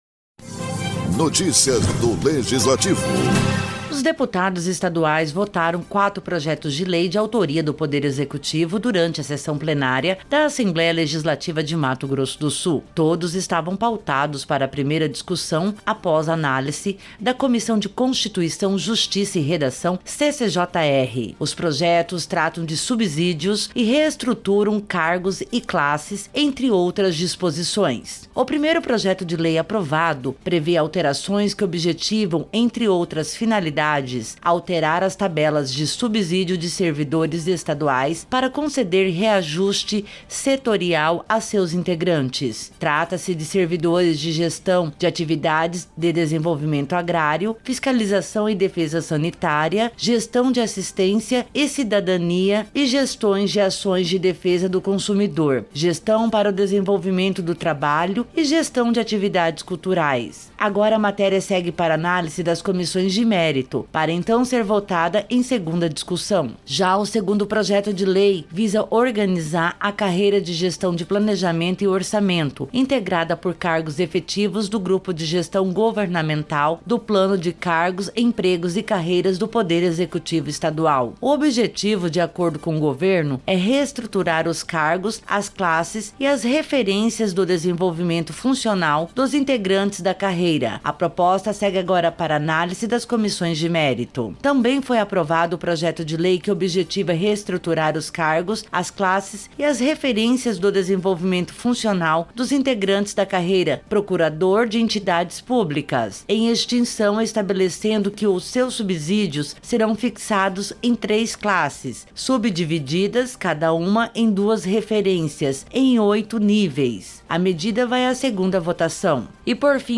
Os deputados estaduais votaram quatro projetos de lei de autoria do Poder Executivo durante a sessão plenária da Assembleia Legislativa de Mato Grosso do Sul.